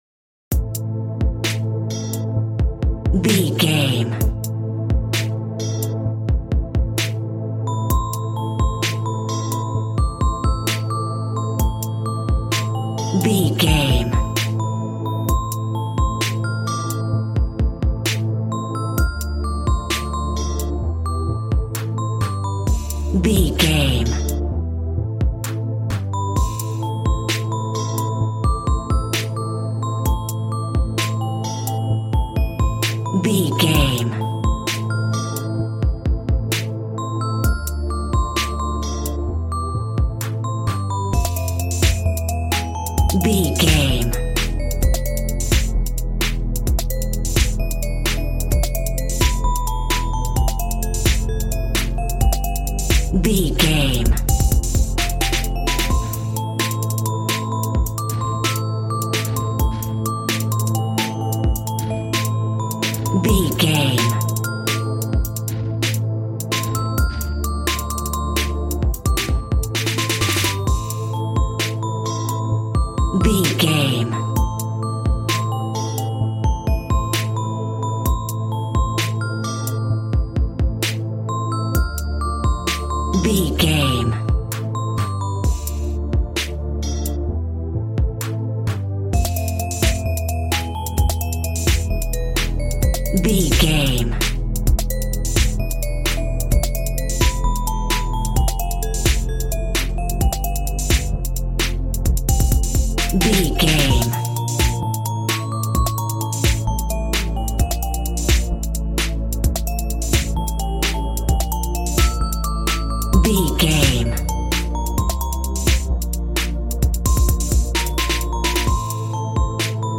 Aeolian/Minor
calm
smooth
synthesiser
piano